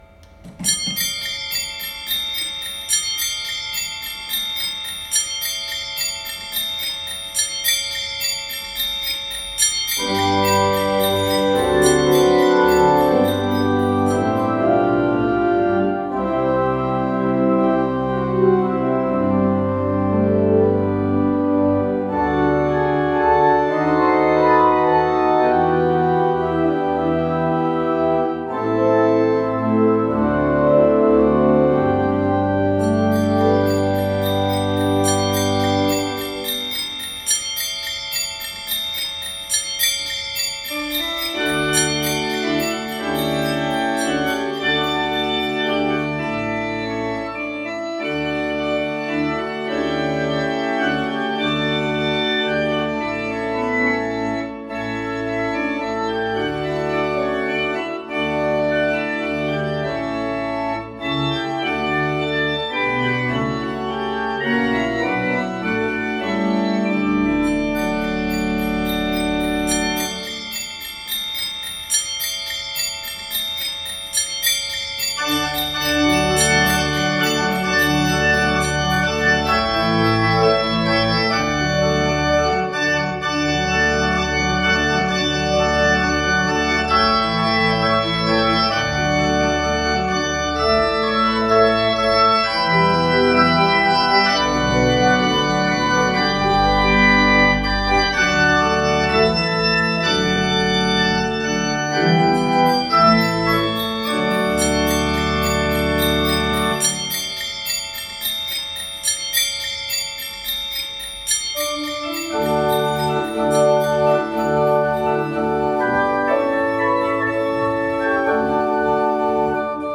Zimbelstern mit Klangbeispiel
Der Zimbelstern ist ein dekoratives und klangliches Element in vielen Kirchenorgeln. Er besteht aus einem rotierenden Stern mit kleinen Glöckchen, die beim Drehen ein zartes, funkelndes Klangspiel erzeugen. Das Musikmedley
Weihnachtsmusik2024.mp3